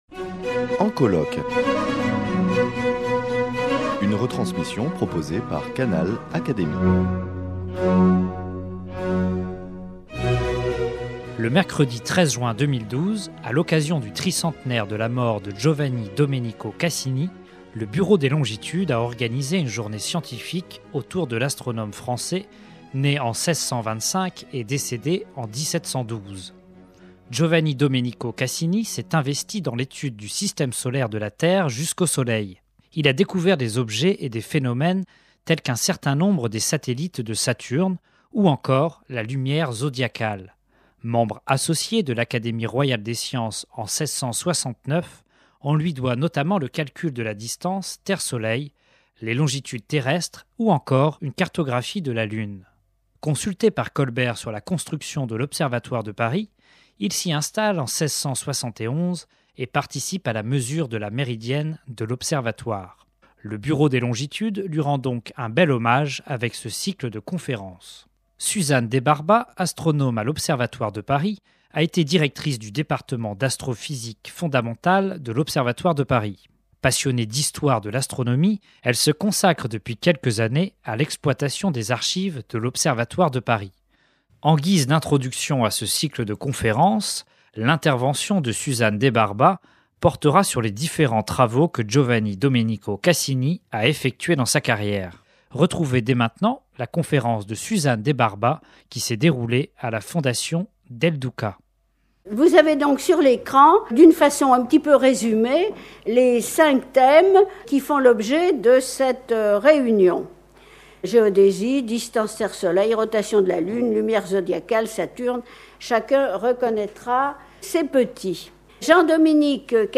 Le mercredi 13 juin 2012, à l’occasion du tricentenaire de la mort de Giovanni-Domenico Cassini, le Bureau des Longitudes a organisé une journée scientifique autour de l’astronome français né en 1625 et décédé en 1712.